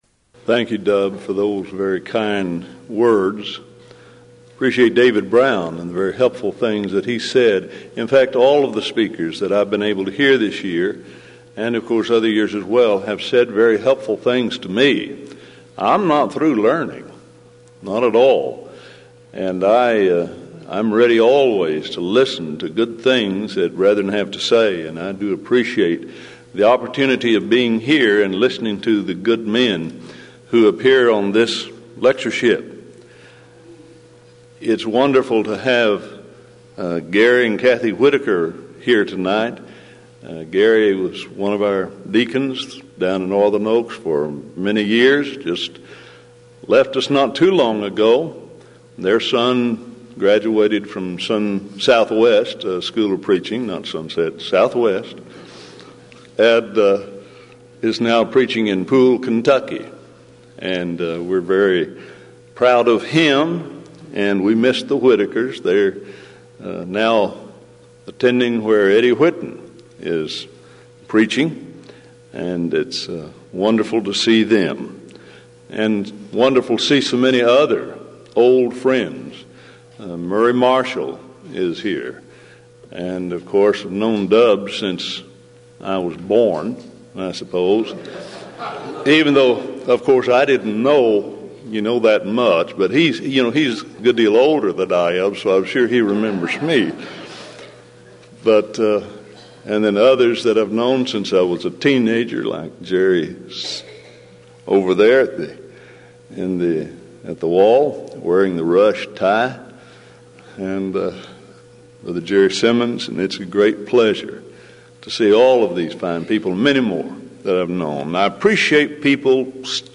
Event: 16th Annual Denton Lectures